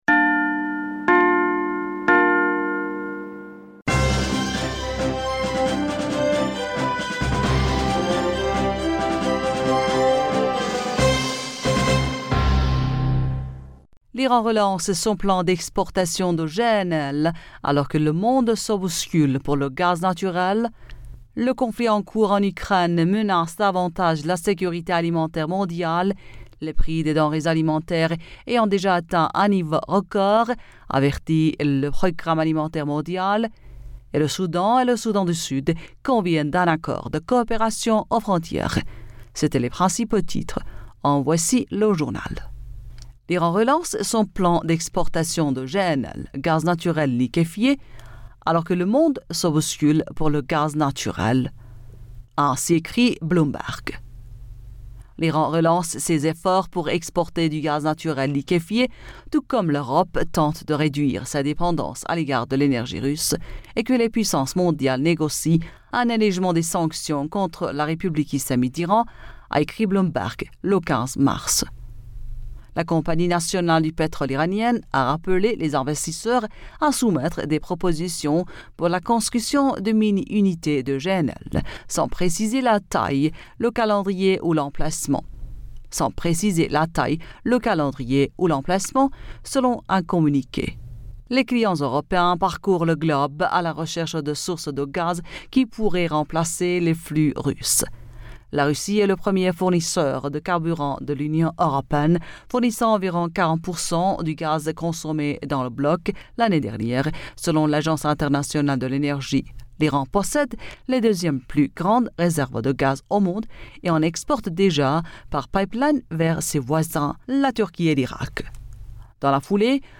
Bulletin d'information Du 19 Mars 2022